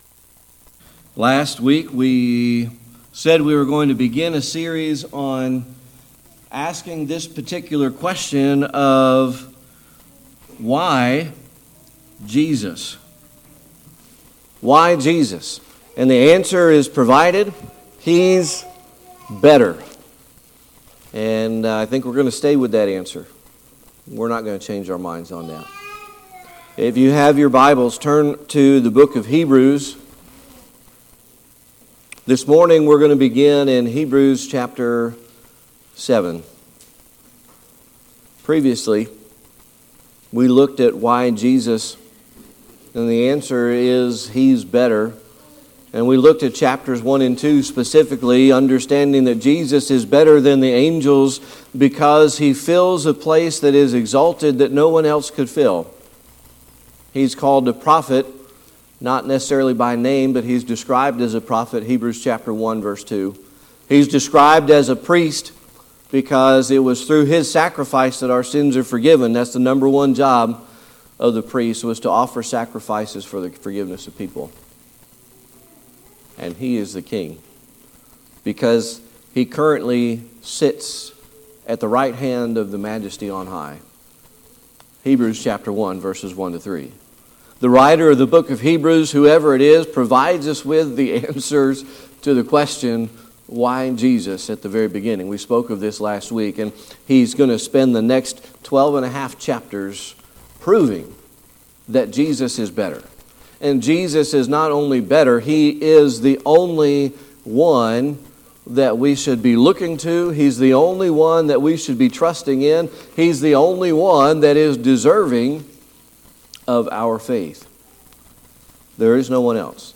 Hebrews 7:19 Service Type: Sunday Morning Worship Last week we said we were going to begin a series on asking this particular question of Why Jesus?